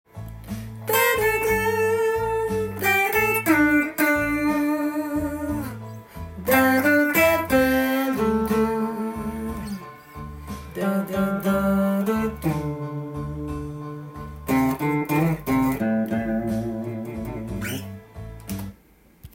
ブルース系だとこのような雰囲気です。
blues.utau2_.m4a